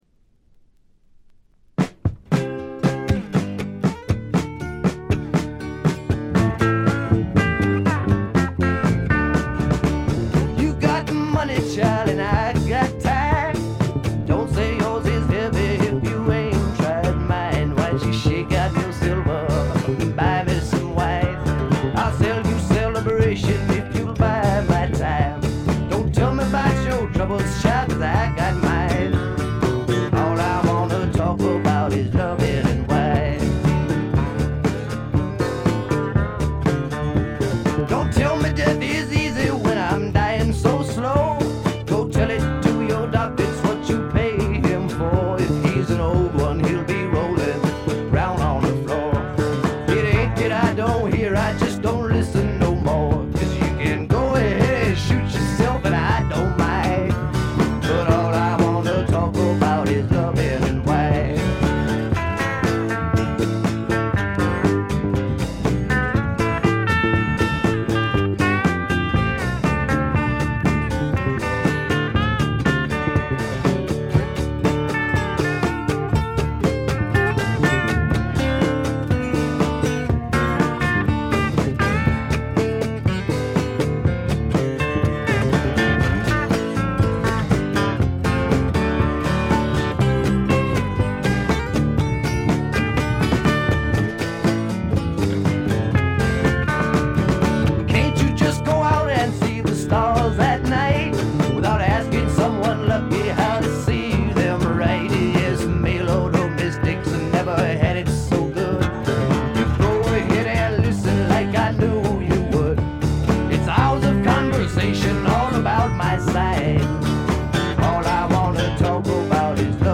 軽微なバックグラウンドノイズ、チリプチ程度。
試聴曲は現品からの取り込み音源です。